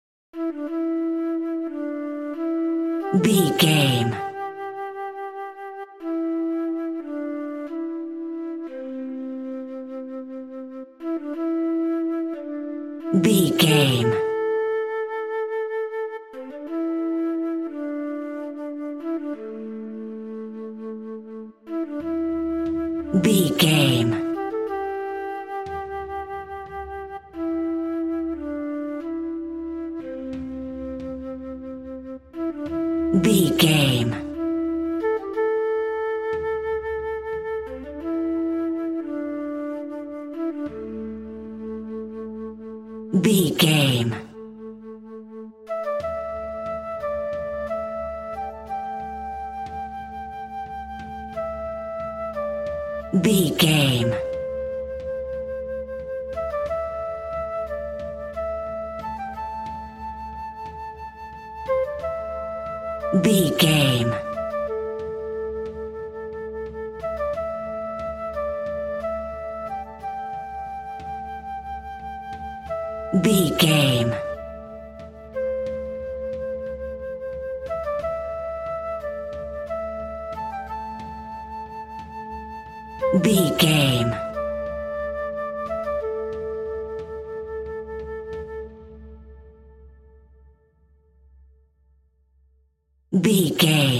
Aeolian/Minor
Slow